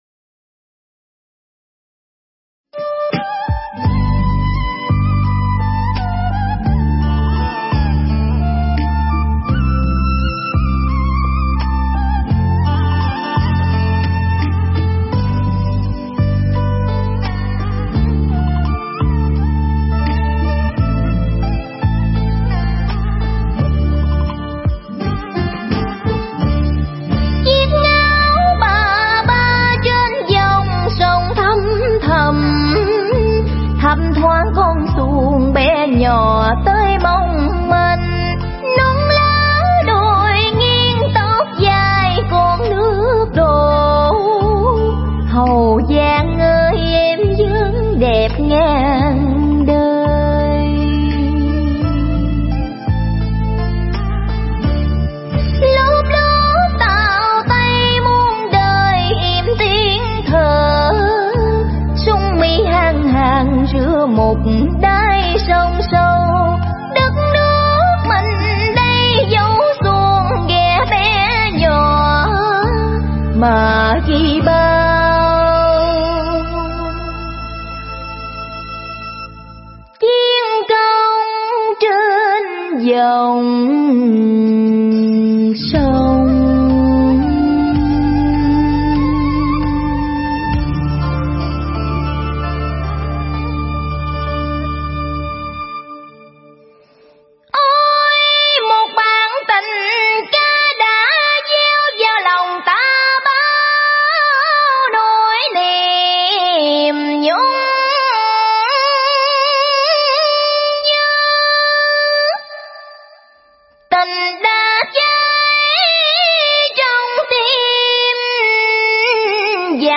Tuyển Chọn Dân Ca Ví Dặm Mp3 Cổ
Dân Ca Xứ Nghệ Mới Nhất 2021.